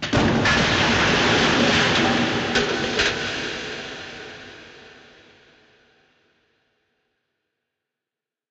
BoomFall_ECHO.ogg